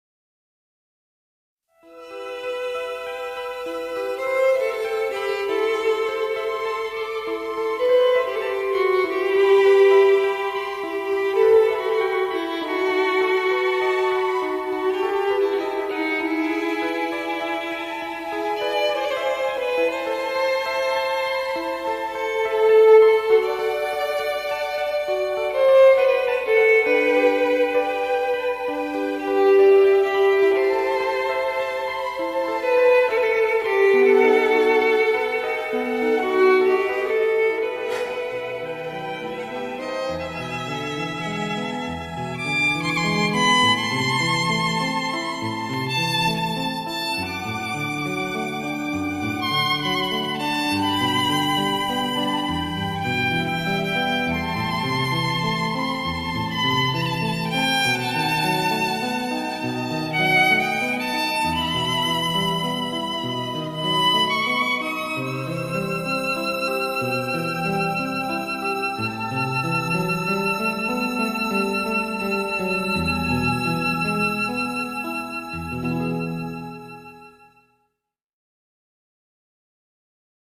tema dizi müziği, duygusal hüzünlü rahatlatıcı fon müzik.